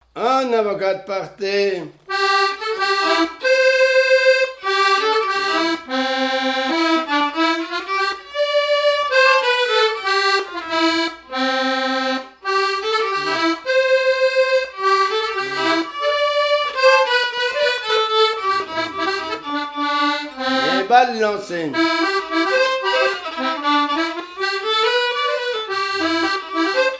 danse : quadrille : avant-quatre
Pièce musicale inédite